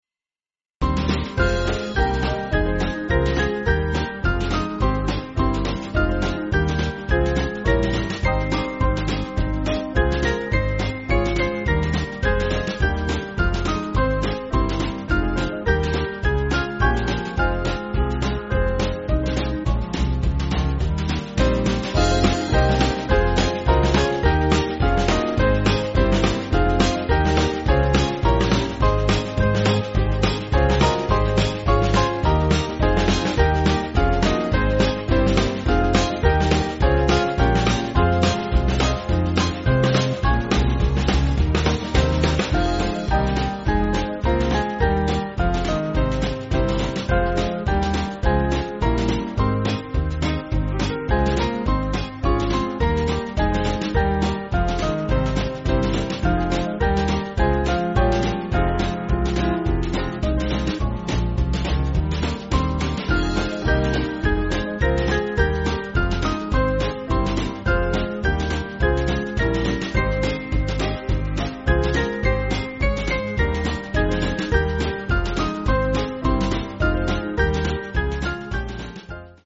Small Band
(CM)   5/Fm-Gbm